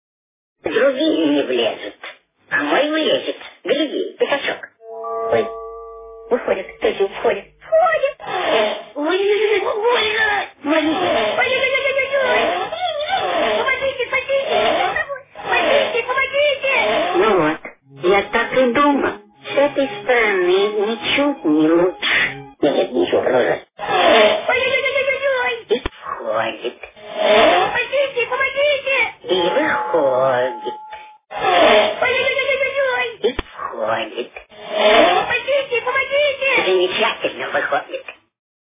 нарезка из мульта про Винни-Пуха